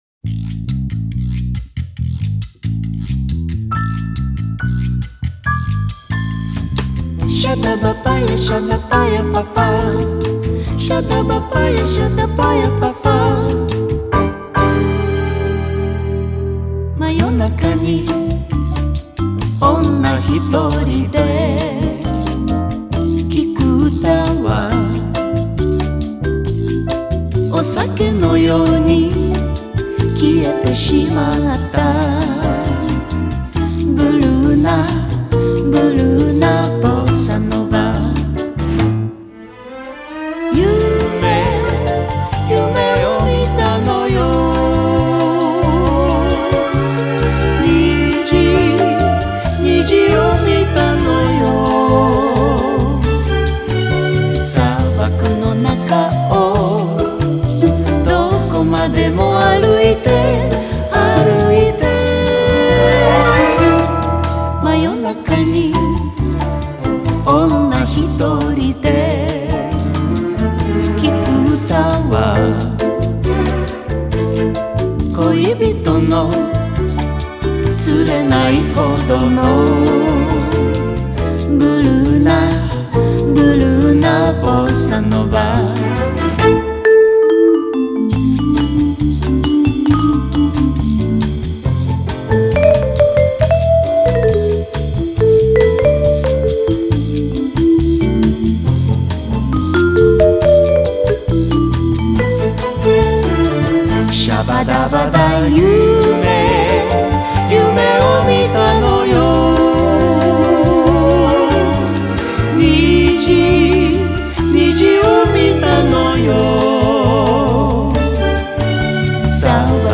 ジャンル: Jazz